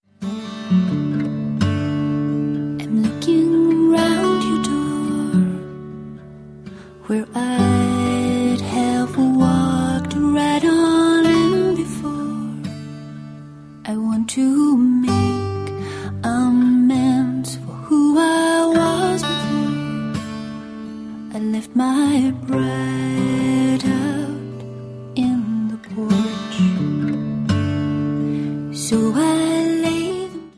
• Sachgebiet: Celtic